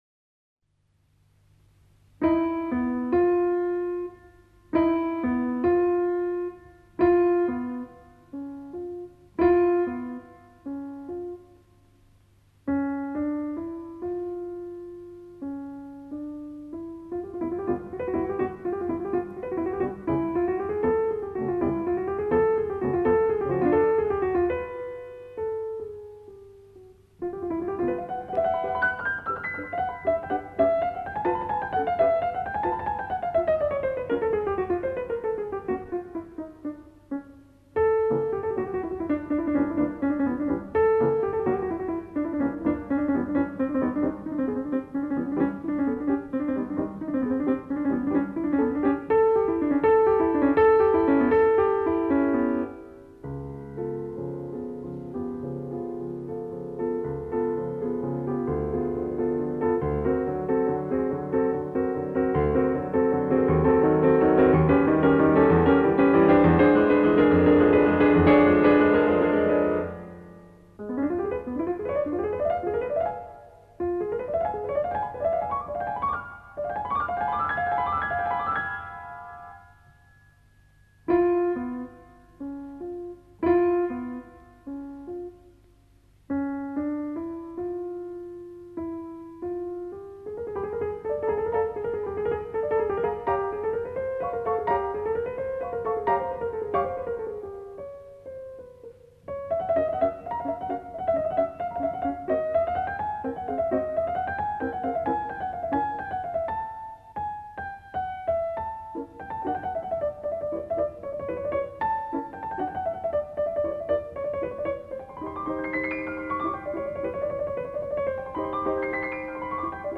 piano, radionauhoitus 1968.